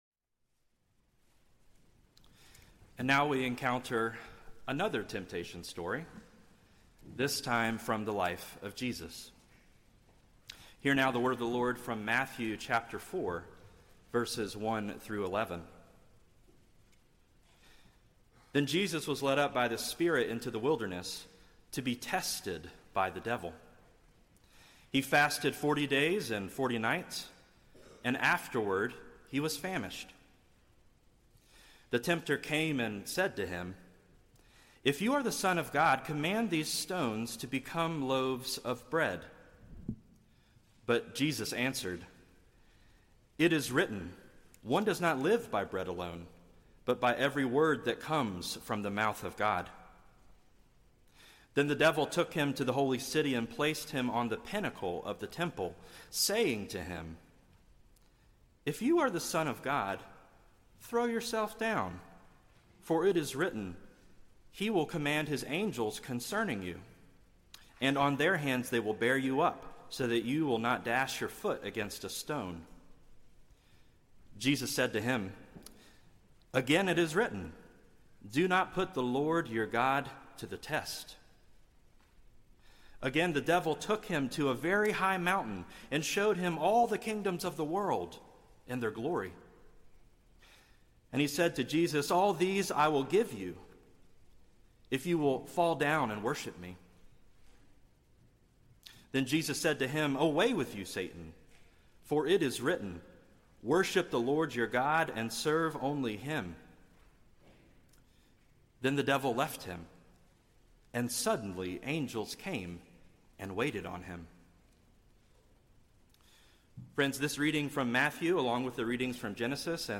Feb22Sermon.mp3